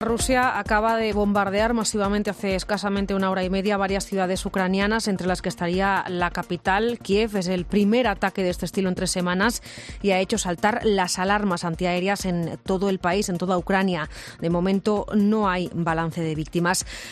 Las sirenas antiaéreas sonaron en toda Ucrania, incluida la capital, Kiev